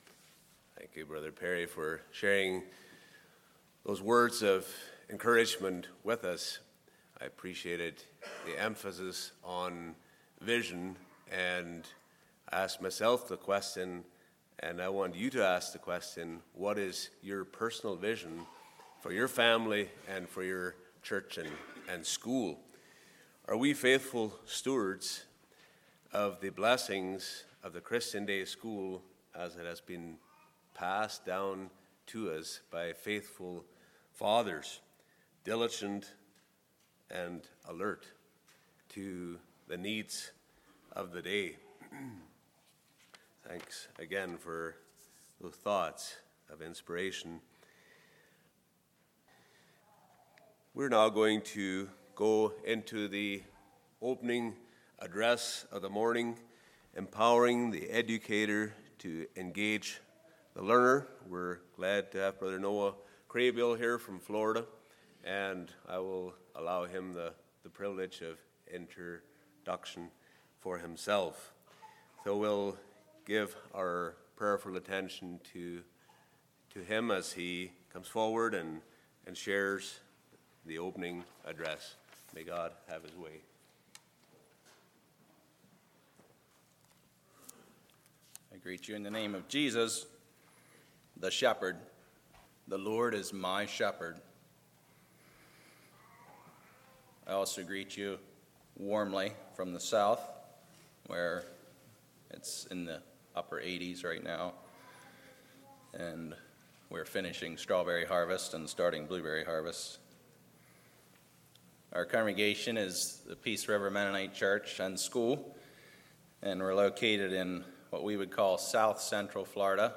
Home » Lectures » Empowering the Educator to Engage the Learner